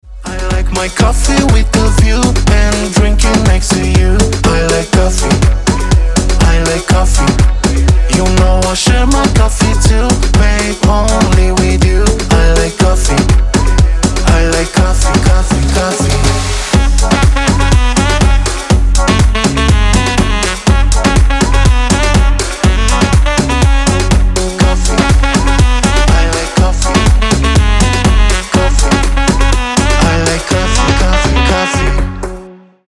Забавная песня про любовь к кофе